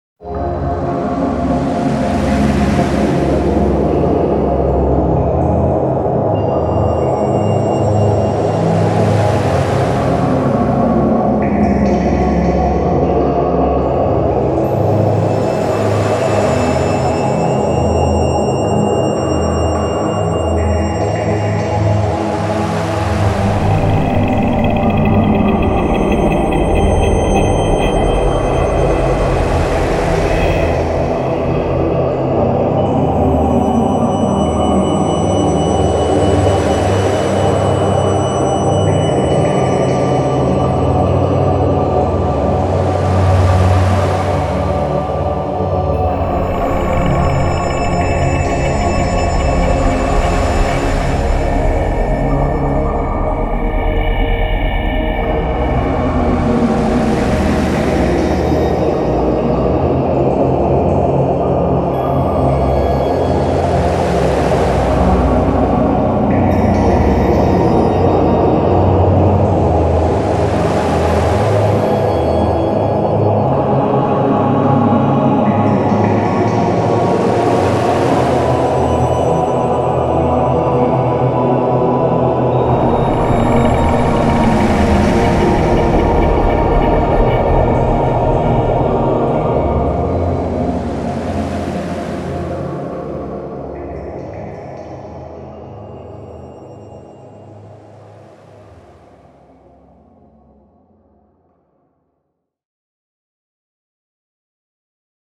🎵 something chilling: